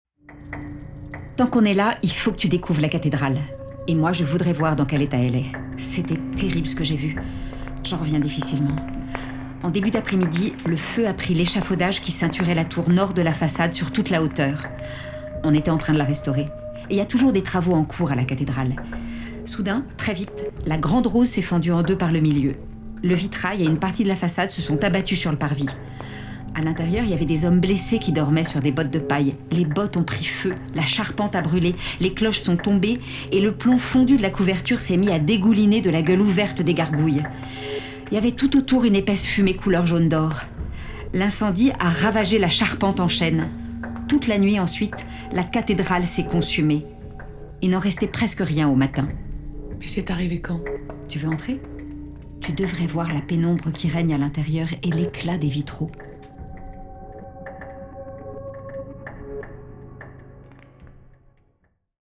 Une conversation est une fiction sonore géolocalisée qui invite à suivre deux femmes au fil de leur déambulation rémoise.
Une fiction sonore disponible en 2024 sur l’application dédiée – GOH